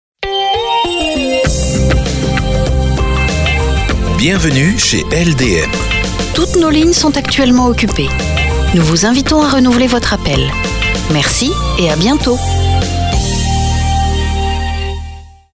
Message répondeur professionnel